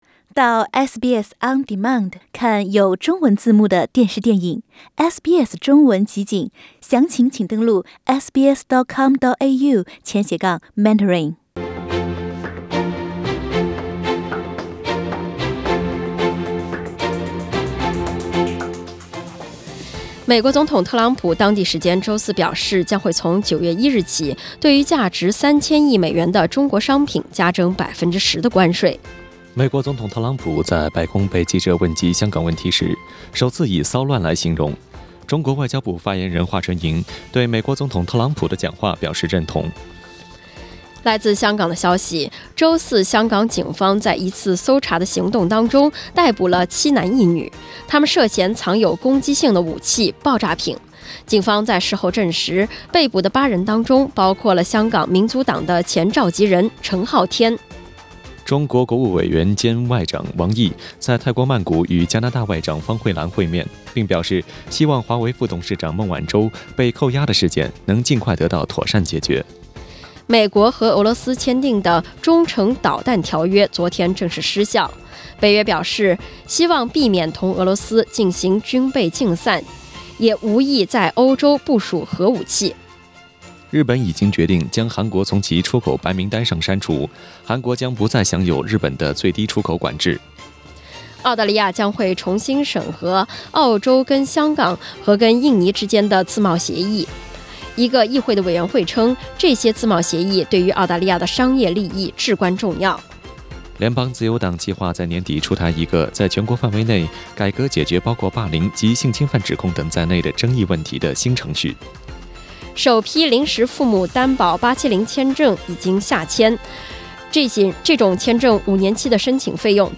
SBS早新聞(8月3日)